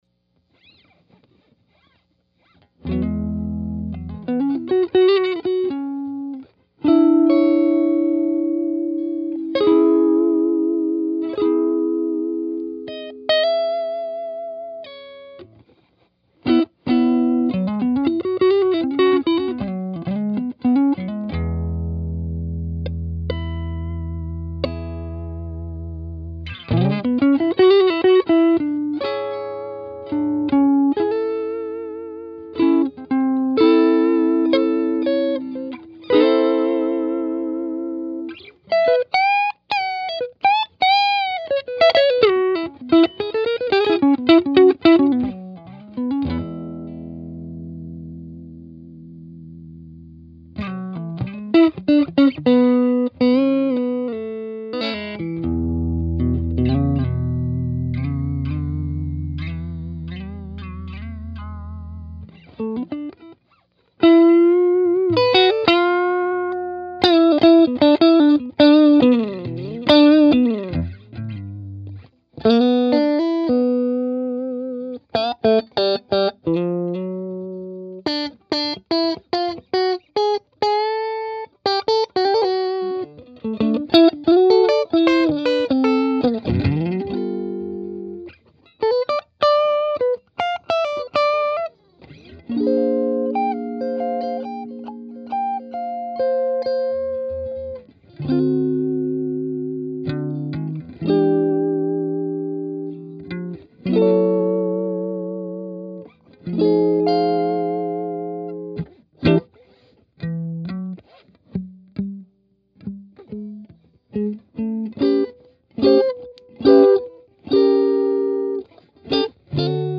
These amps usually break into pretty good power tube distortion as you turn them up.
Clean
Trinity_deluxe_clean_strat.mp3